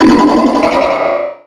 Cri de Méga-Charmina dans Pokémon X et Y.
Cri_0308_Méga_XY.ogg